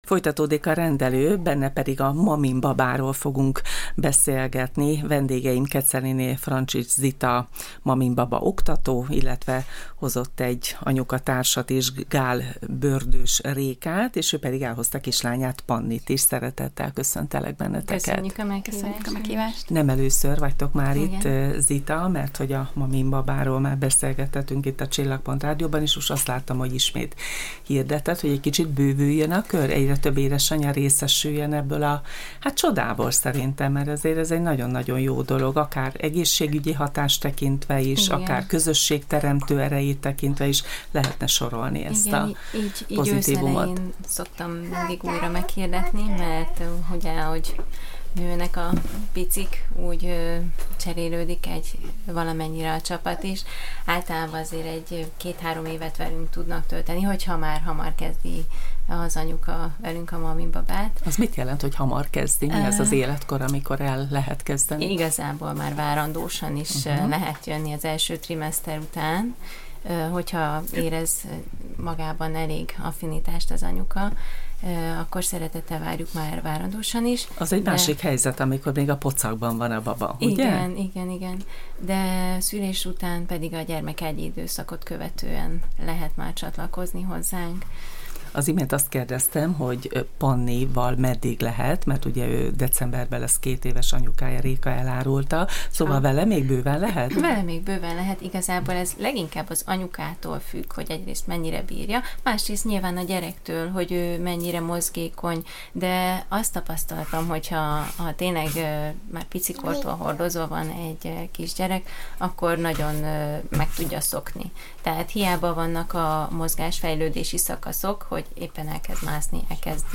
A jóhangulatú beszélgetésen elhangzott: a maminbaba célja az anyukák jól-léte, hogy kikapcsolódva mozoghassanak, miközben a babájuk is jól érzi magát.